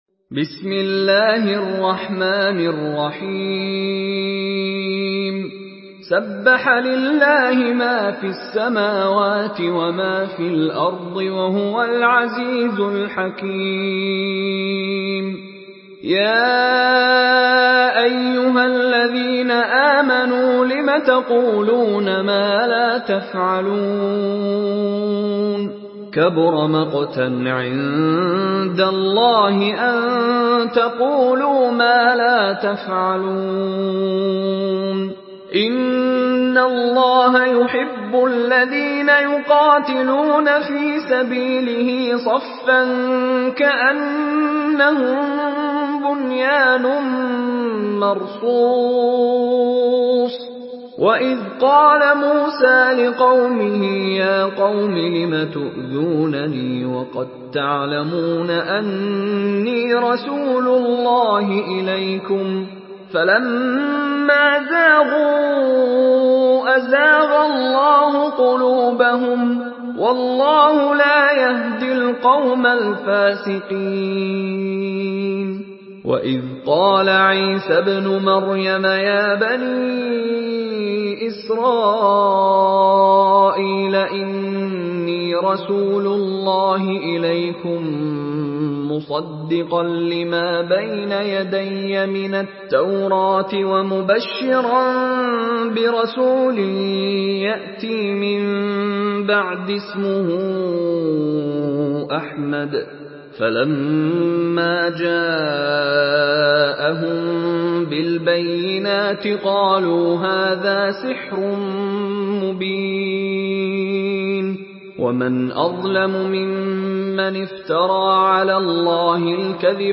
Surah আস-সাফ MP3 by Mishary Rashid Alafasy in Hafs An Asim narration.
Murattal Hafs An Asim